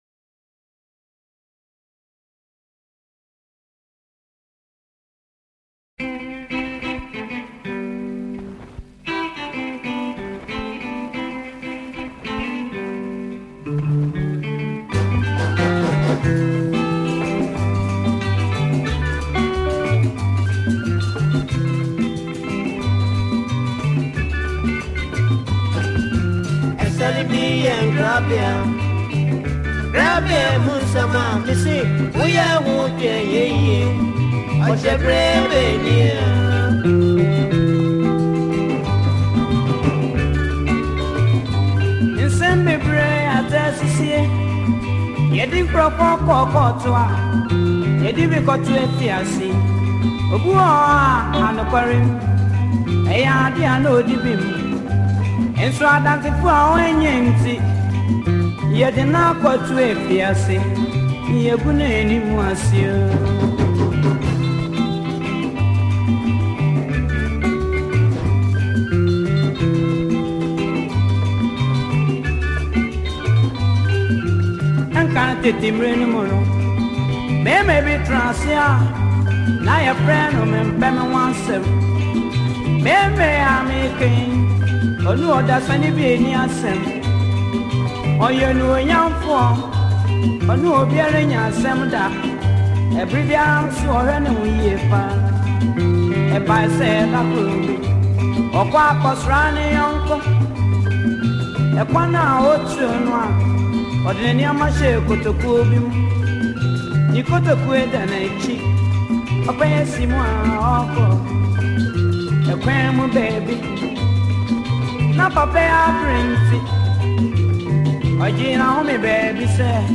an old highlife song